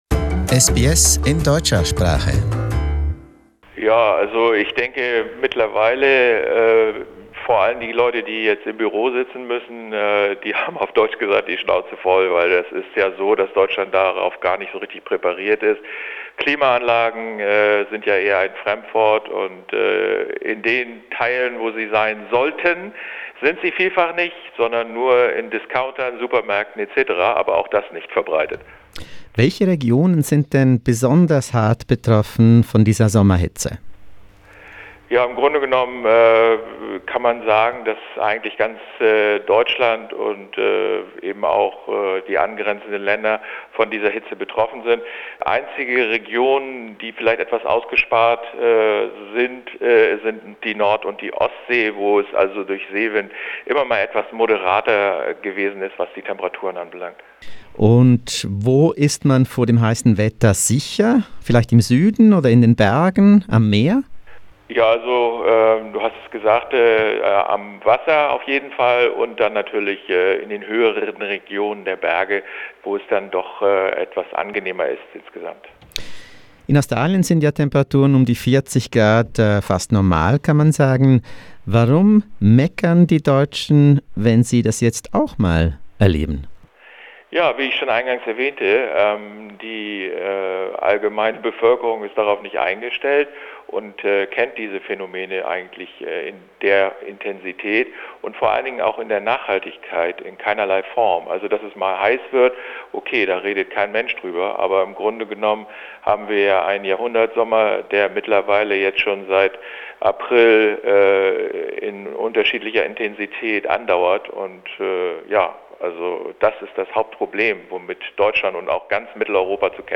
28 Degrees - Germany's hottest night on record: An interview